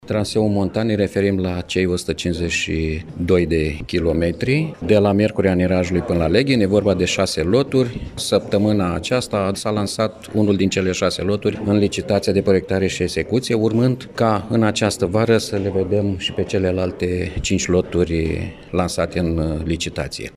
Astăzi, într-o conferință de presă, susținută la Iași,